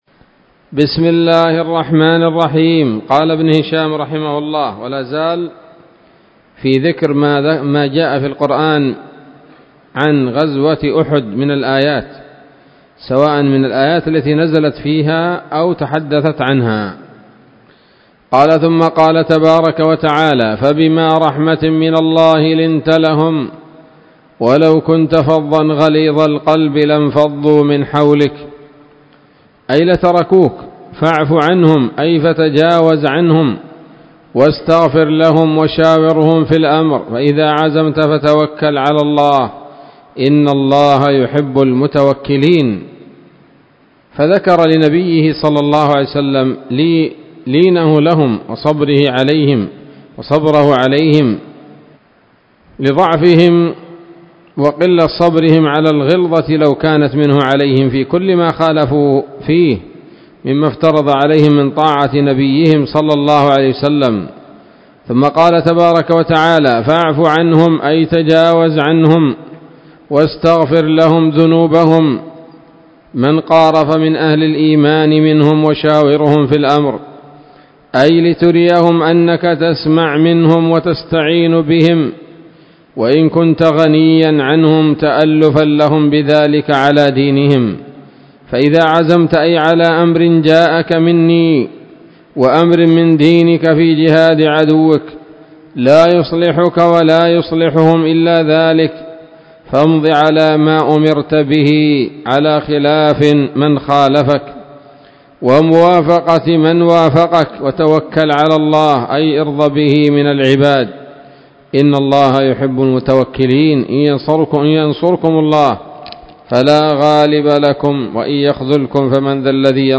الدرس الثاني والسبعون بعد المائة من التعليق على كتاب السيرة النبوية لابن هشام